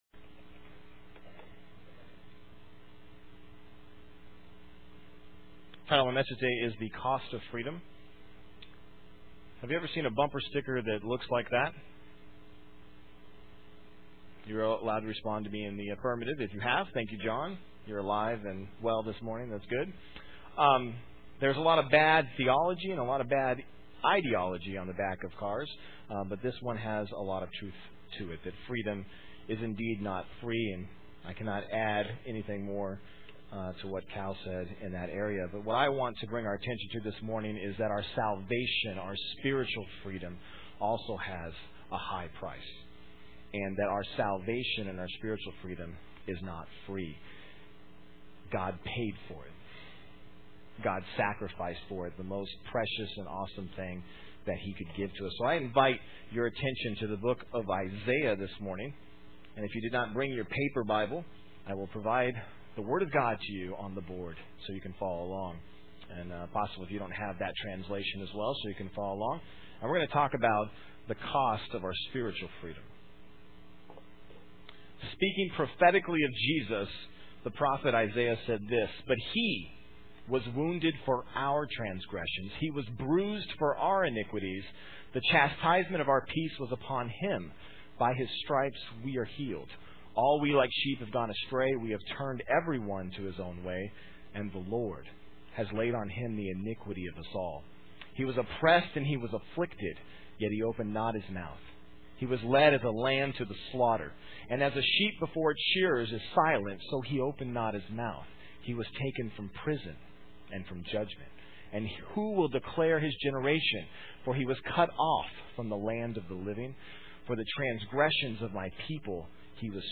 Main Service am